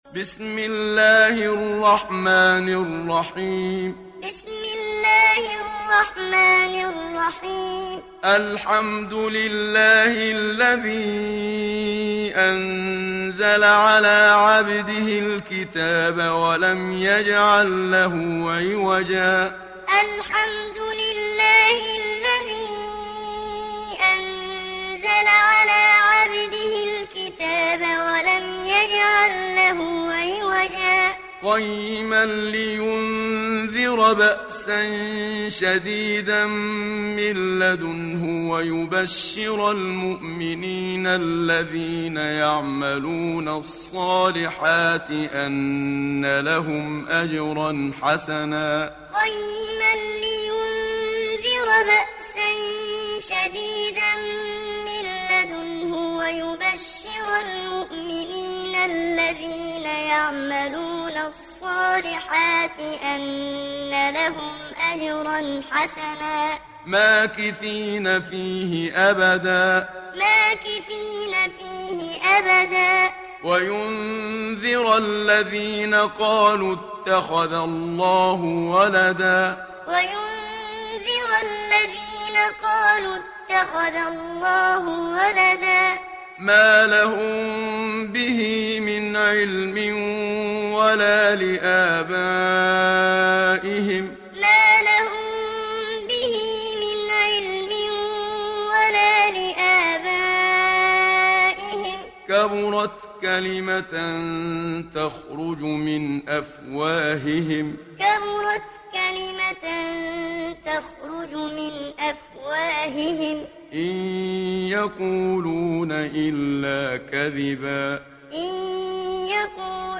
Kehf Suresi mp3 İndir Muhammad Siddiq Minshawi Muallim (Riwayat Hafs)